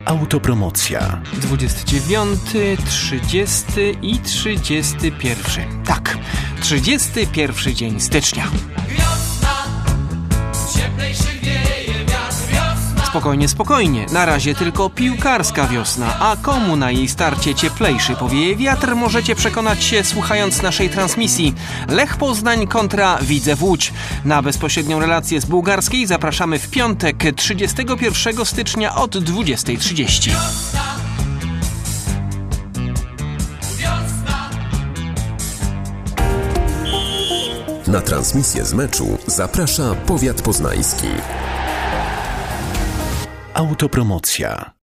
Radiowe zapowiedzi meczów